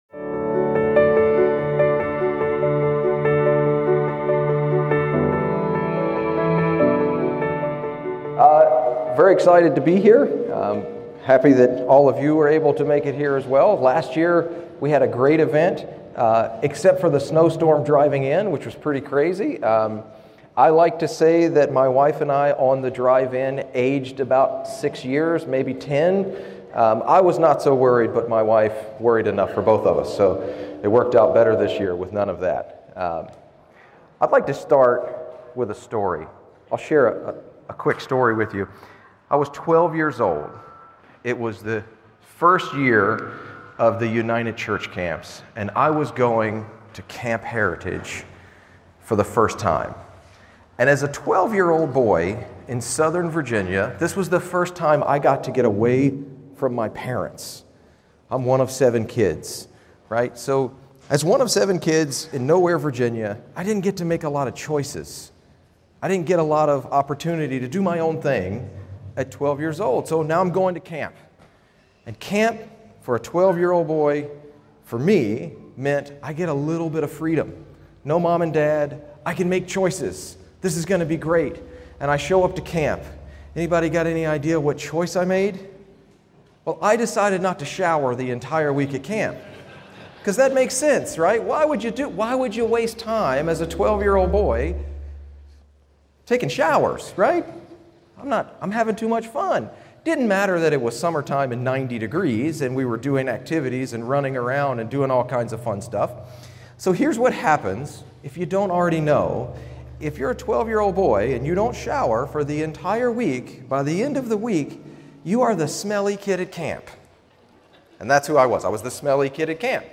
Winter Family Weekend Seminar
Given in Cincinnati East, OH